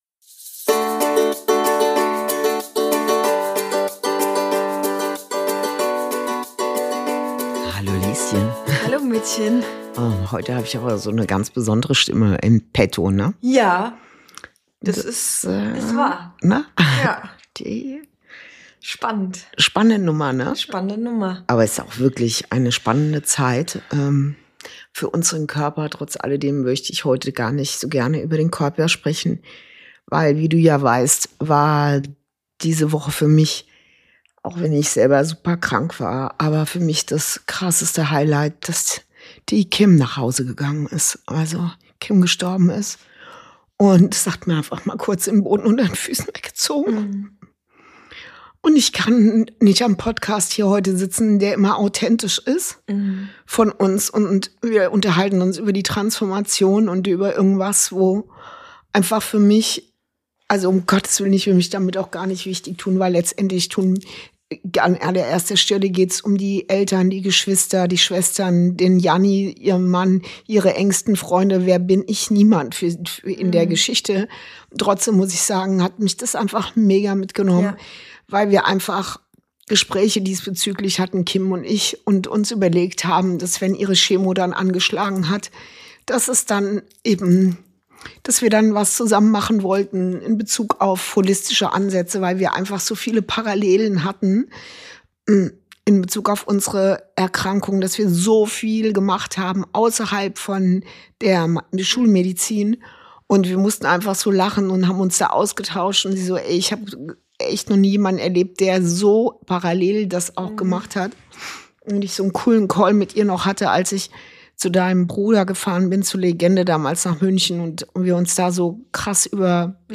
Folge 38: Zwischen Tod, Schmerz und Weitergehen – eine Woche, die nachhallt ~ Inside Out - Ein Gespräch zwischen Mutter und Tochter Podcast